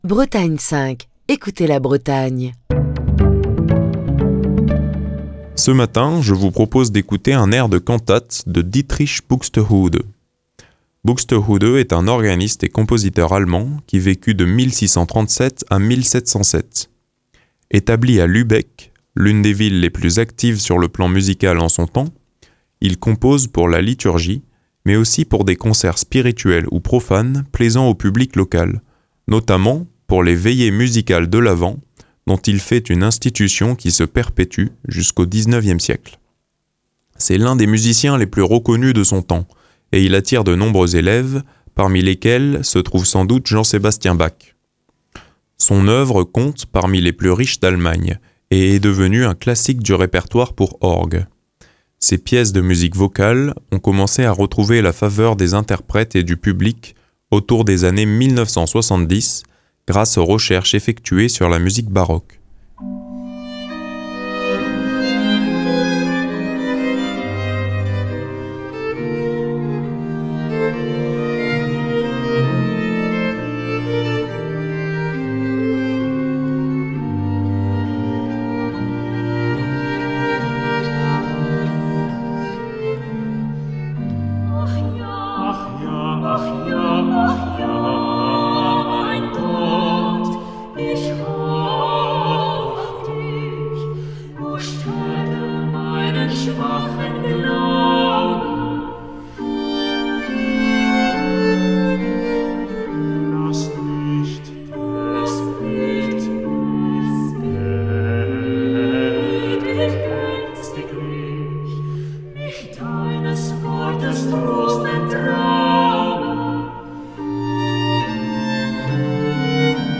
Fil d'Ariane Accueil Les podcasts « Ach ja, mein Gott », un air de la cantate WV34 de Dietrich Buxtehude « Ach ja, mein Gott », un air de la cantate WV34 de Dietrich Buxtehude Émission du 2 avril 2024.
« Ach ja, mein Gott », un air de la cantate WV34 de Dietrich Buxtehude, composé pour les veillées musicales de l’Avent, est interprété par l’ensemble Vox Luminis et l’ensemble Masquee en 2018. Deux ensembles spécialisés dans l’interprétation du répertoire baroque et qui ont décidés de collaborer, pour notre plus grand plaisir.